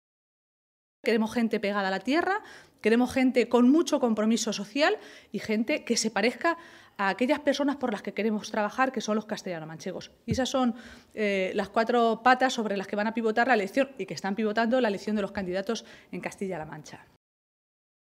Maestre se pronunciaba de esta manera esta tarde, en Ciudad Real, minutos antes de que comenzara la reunión de la ejecutiva regional socialista, presidida por García-Page en esa ciudad manchega.